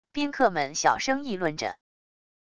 宾客们小声议论着wav音频